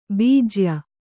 ♪ bījya